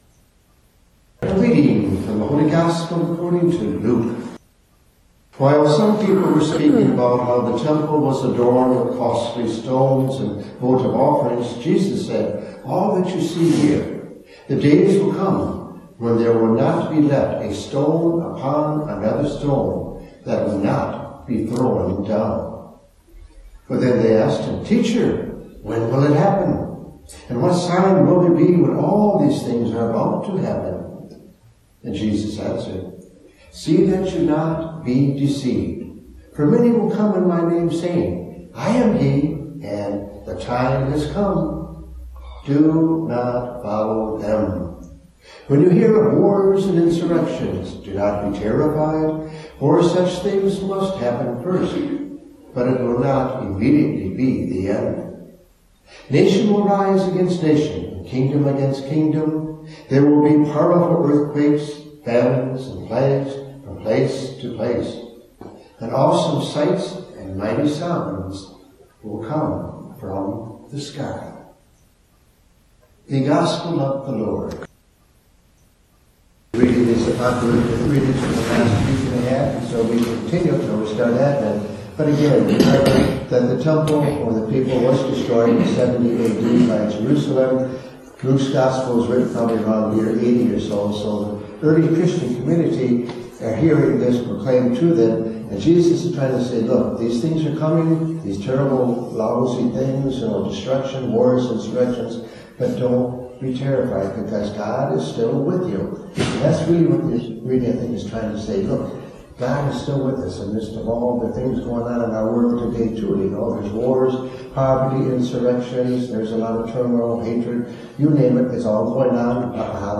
Homilies – November 2019